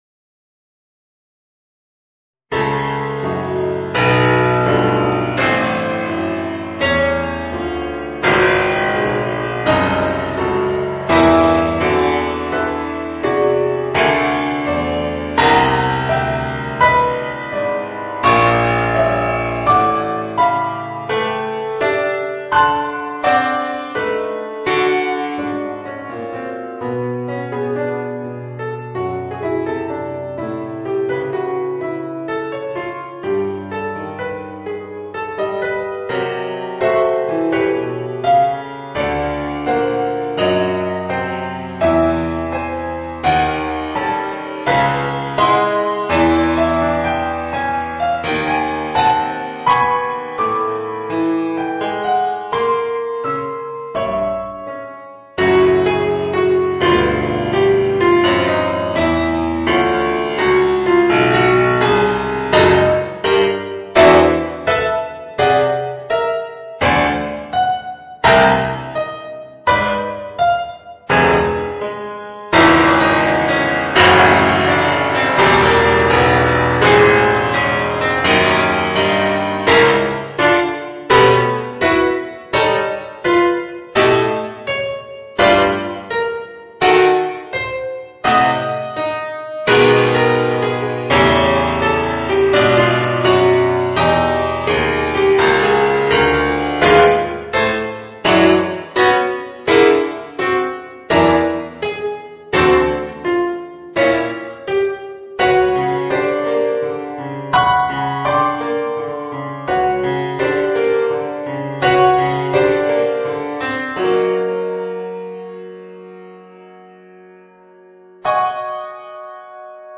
Voicing: Piano Solo